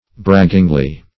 braggingly - definition of braggingly - synonyms, pronunciation, spelling from Free Dictionary Search Result for " braggingly" : The Collaborative International Dictionary of English v.0.48: Braggingly \Brag"ging`ly\, adv.